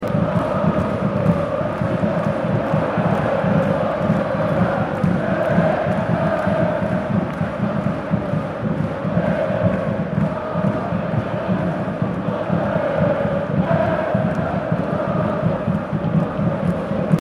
جلوه های صوتی
دانلود صدای استادیوم از ساعد نیوز با لینک مستقیم و کیفیت بالا